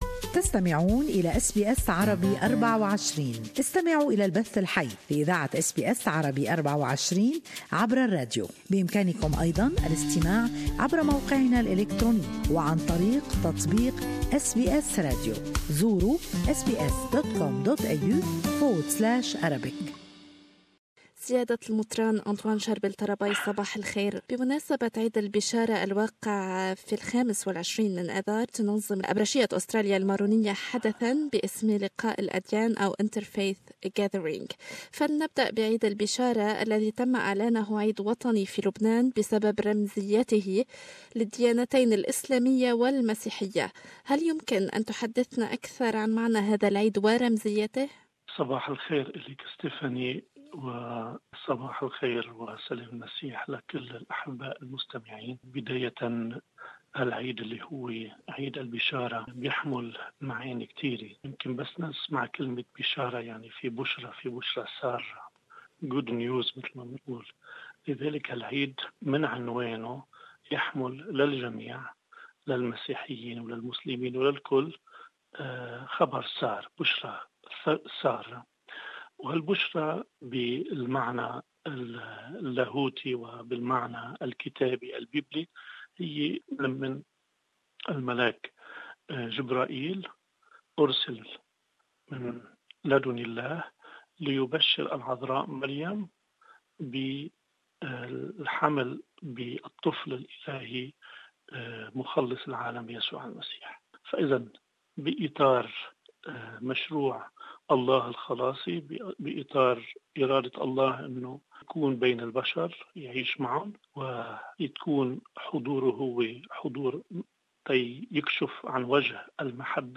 لسماع تفاصيل المقابلة يمكنكم الضغط على التدوين الصوتي أعلاه.